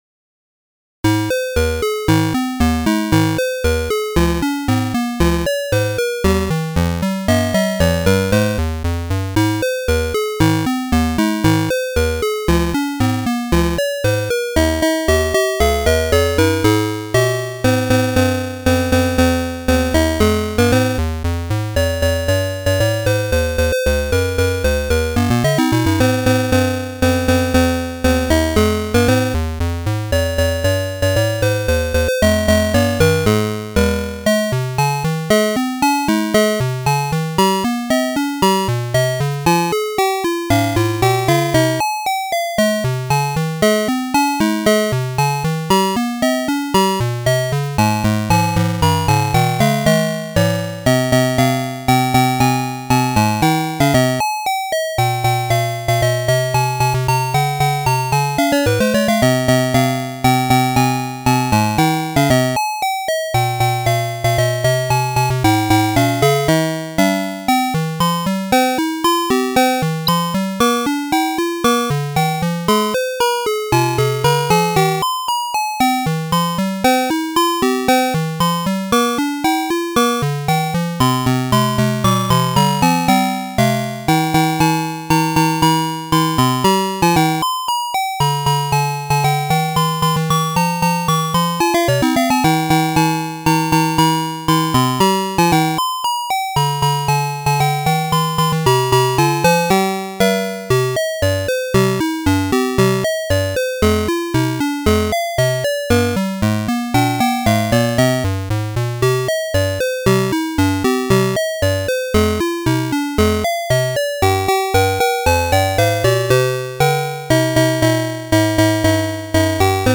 Atari-ST Emulation